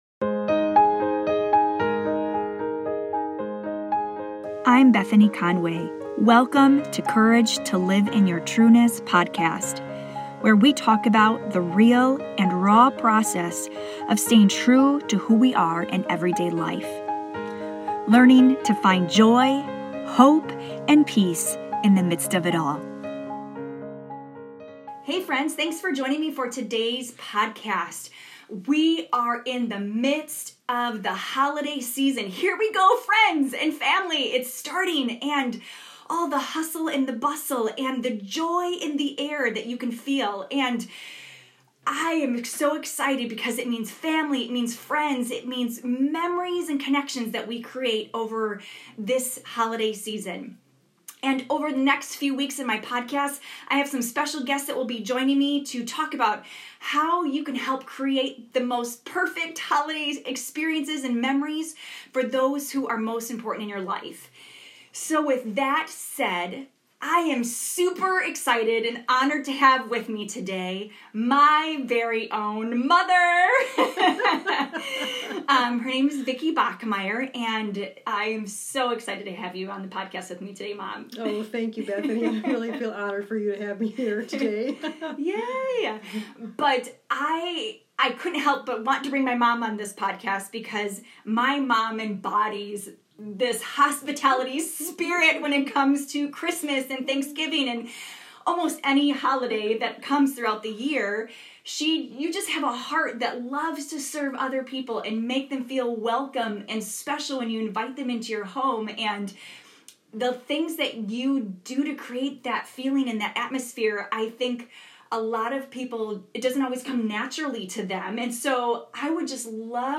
Enjoy their laughter and walk down memory lane as they share fun ideas for welcoming guests into your home for the holidays. You can create meaningful holiday moments with simple thoughtfulness and preparation.